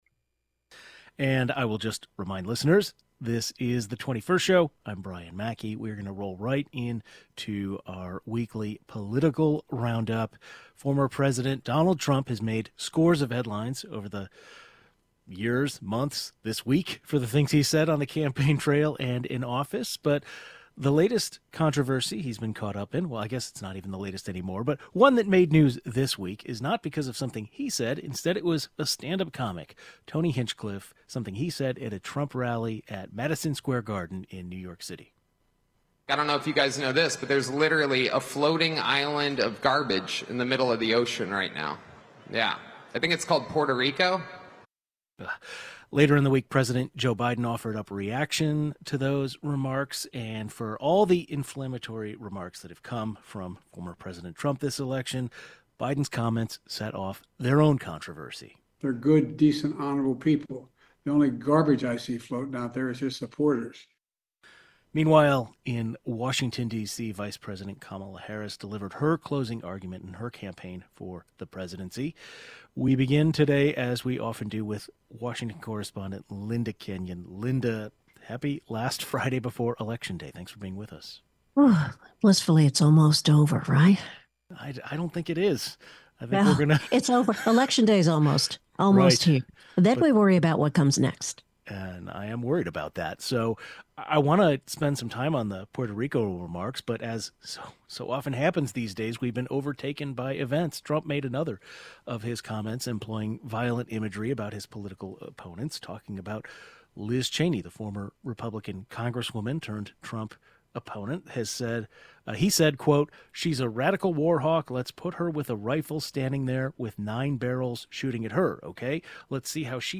It's our Friday politics reporter roundtable: One of the latest controversies involving former President Donald Trump involves anti Puerto Rico comments that stand-up comic Tony Hinchcliffe said at a Trump rally in New York.